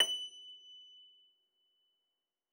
53j-pno24-F5.wav